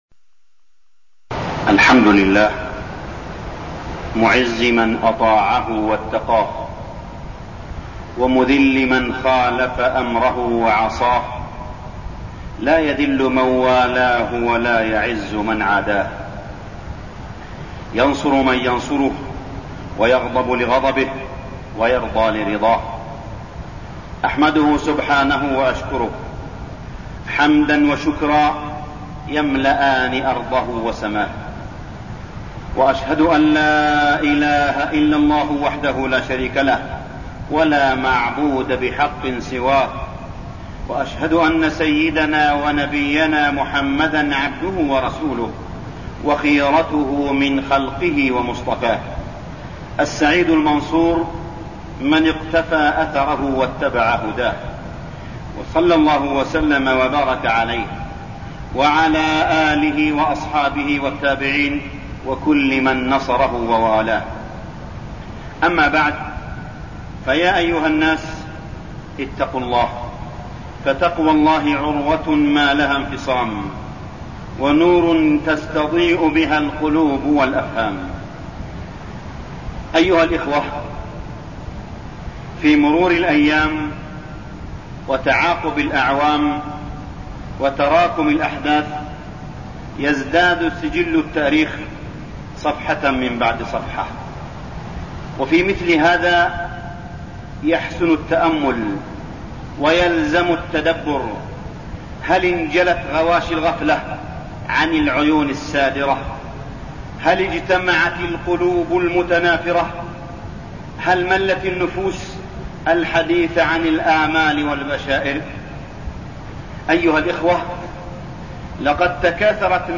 تاريخ النشر ١٦ محرم ١٤١٤ هـ المكان: المسجد الحرام الشيخ: معالي الشيخ أ.د. صالح بن عبدالله بن حميد معالي الشيخ أ.د. صالح بن عبدالله بن حميد أحوال المسلمين في فلسطين